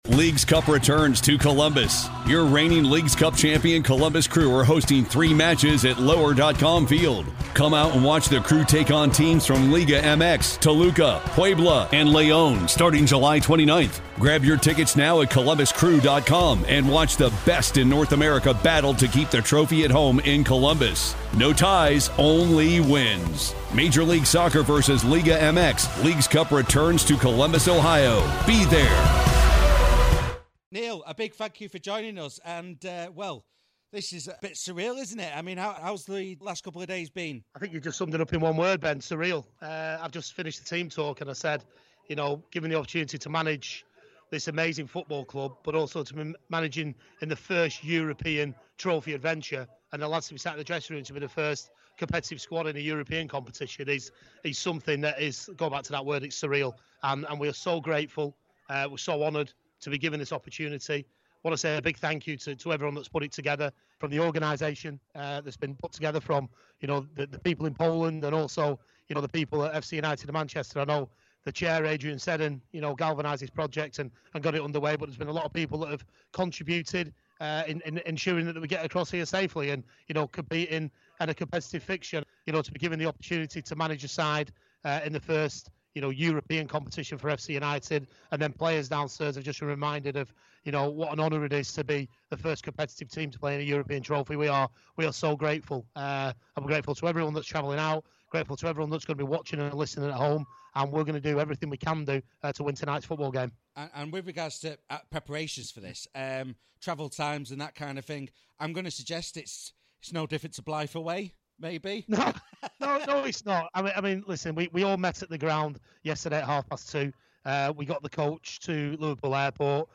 Pre Match Interview